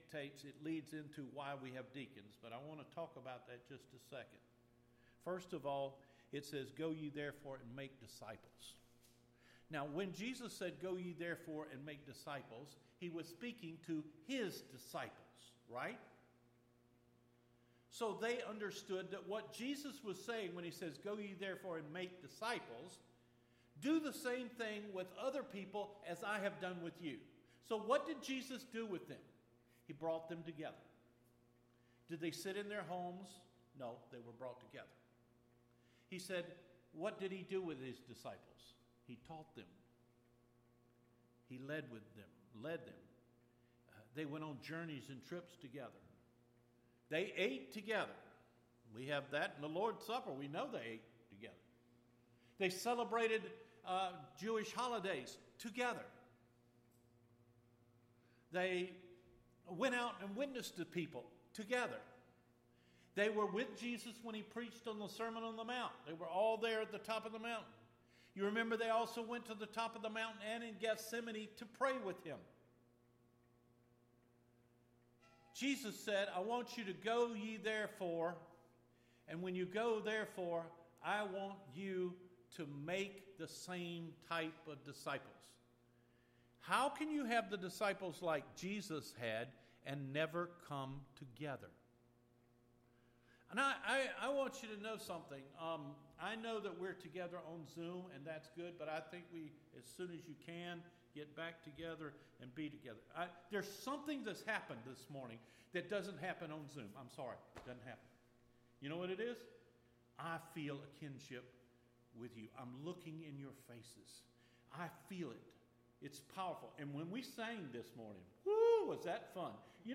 Recorded Sermons No comment WHY CHURCH?